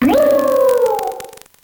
contributions)Televersement cris 5G.